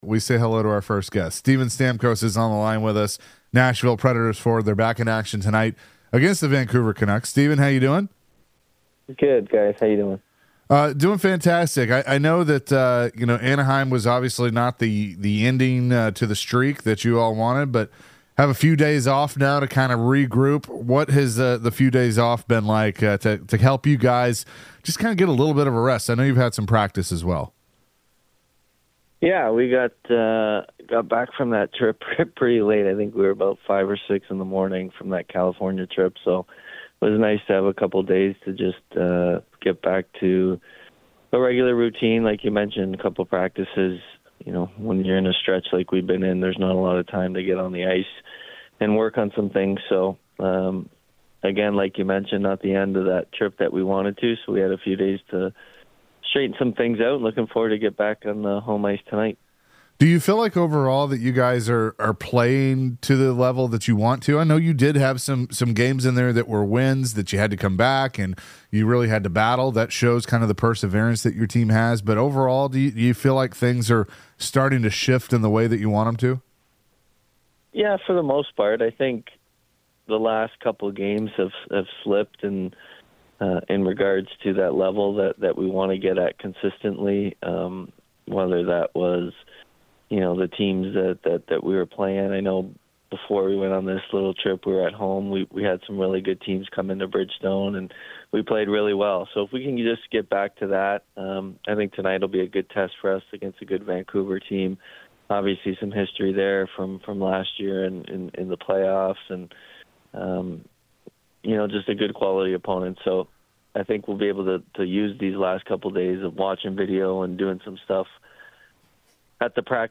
the guys started the show with Preds forward Steven Stamkos discussing the recent form the team has been in. Steven was asked about the possibility of the team making the playoffs.